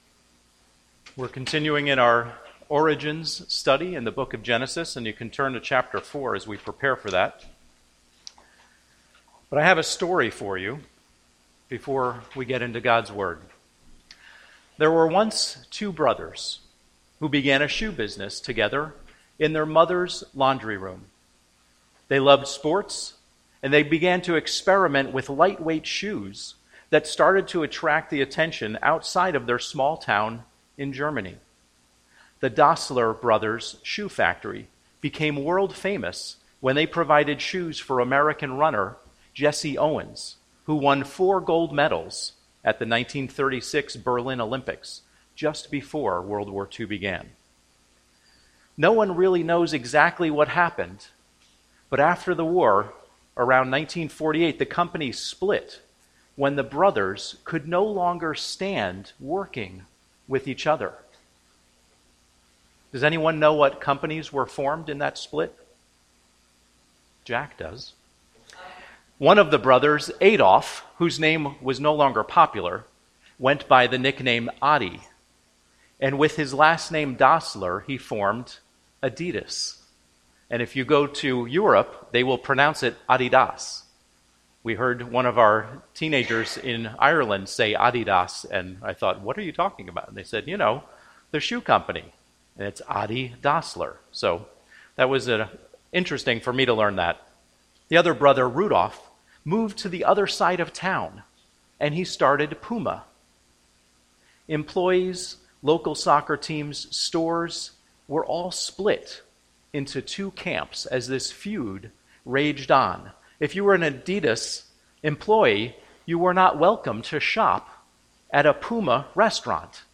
Home › Sermons › Acceptable Worship